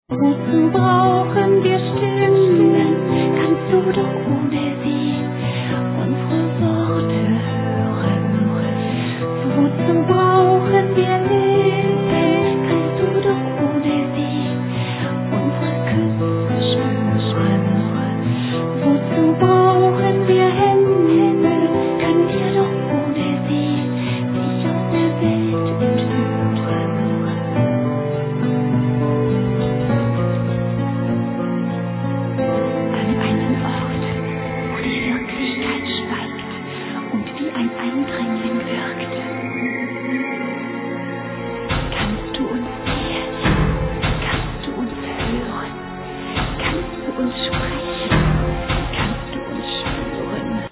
Vocals, Programming
Vocals